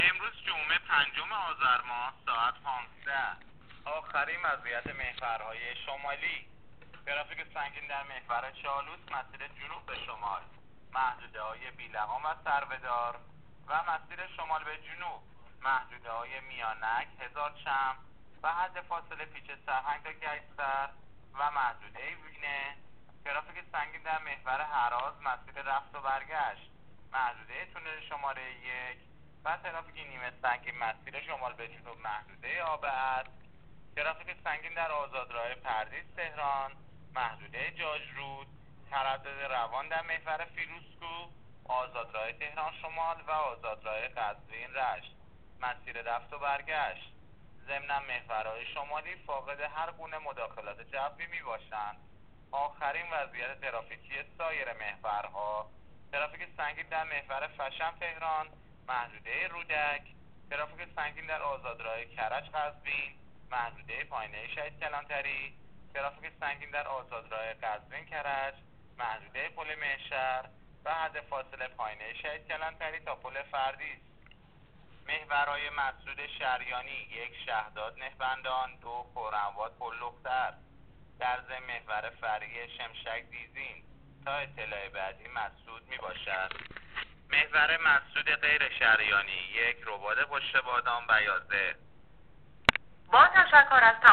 گزارش رادیو اینترنتی از آخرین وضعیت ترافیکی جاده‌ها تا ساعت ۱۵ پنجم آذر؛